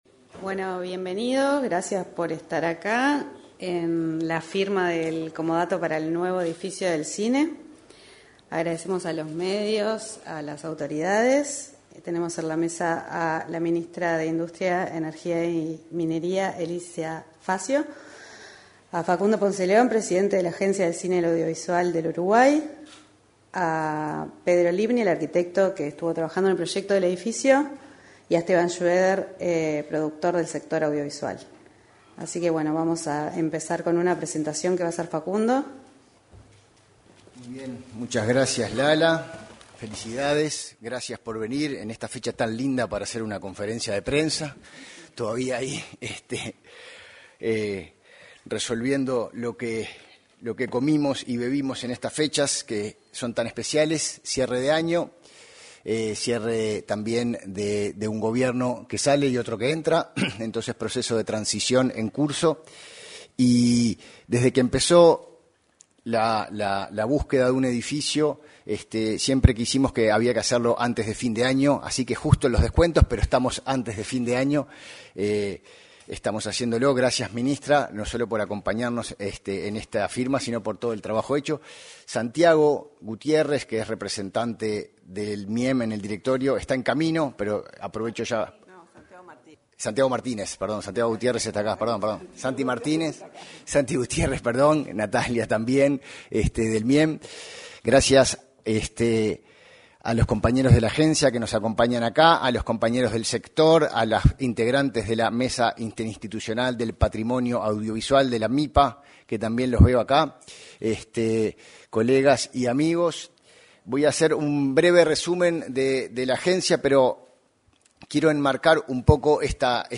Anuncio de comodato para nueva sede de la Agencia del Cine y el Audiovisual del Uruguay
la ministra de Industria, Energía y Minería, Elisa Facio